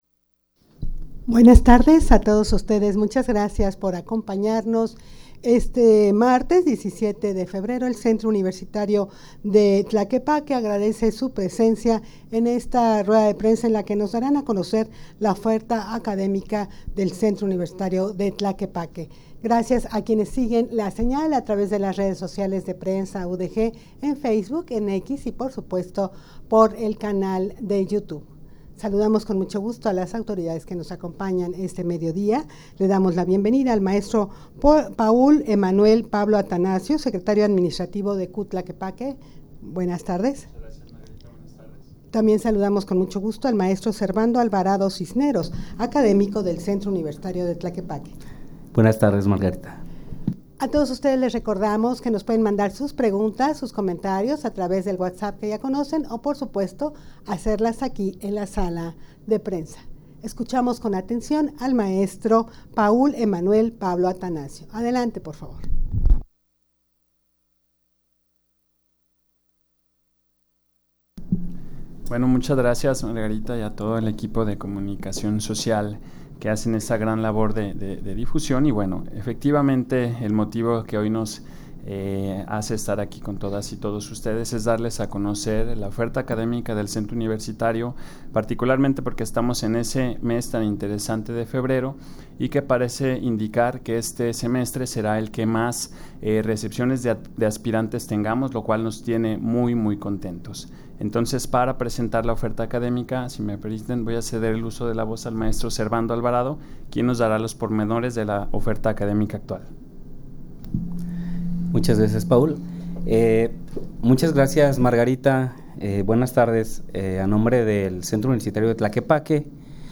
Audio de la Rueda de Prensa
rueda-de-prensa-para-dar-a-conocer-la-oferta-academica-de-cutlaquepaque.mp3